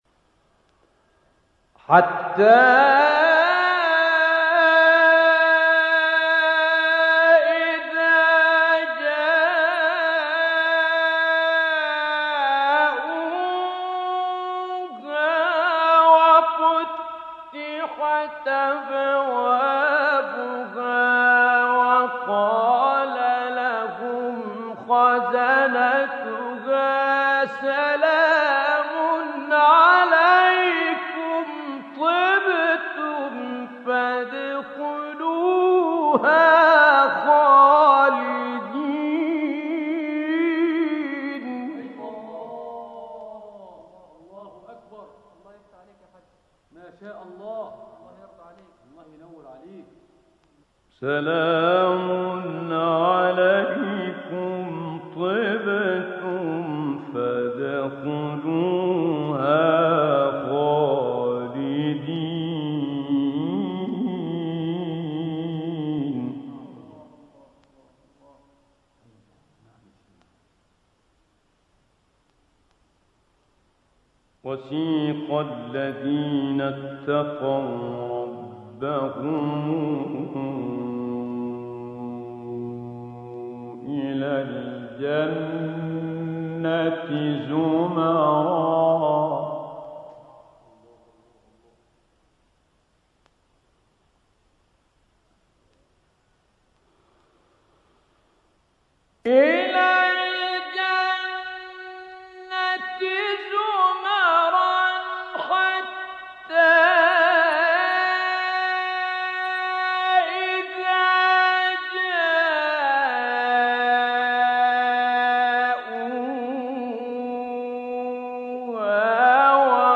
سوره : زمر – غافر آیه: (73-75) – (1-3) استاد : متولی عبدالعال مقام : رست قبلی بعدی